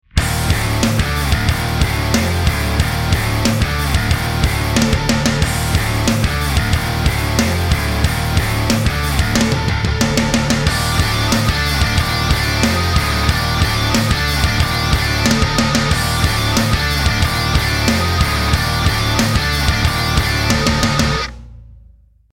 Snare Nu (Song)